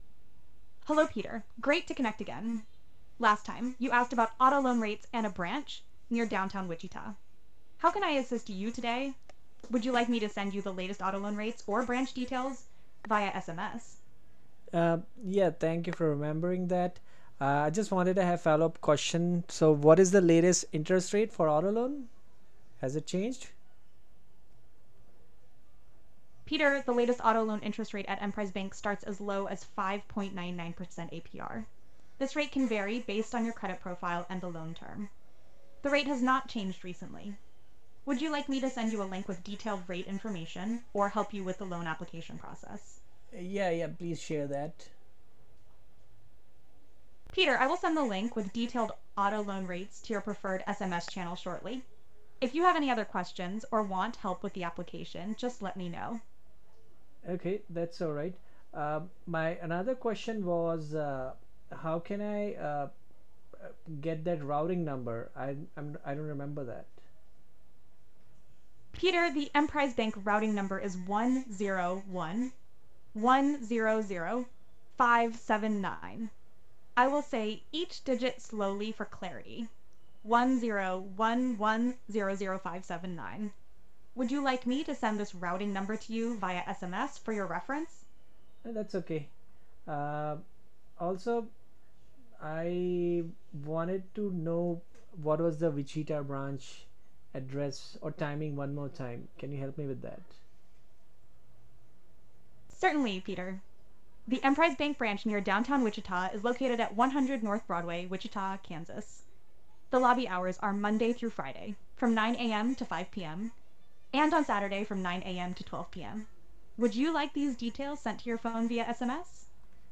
Emprise-VOICE-AI-DEMO-context-History-Made-with-Clipchamp.mp3